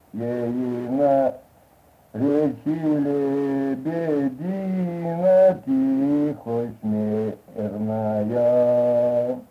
Произношение притяжательного местоимения ейна в соответствии с нормативным притяжательным местоимением её
/йе”-йна ре”-чи л’е:-б’е-д’ии”-на т”ии-ха см’и”-рна-йа:/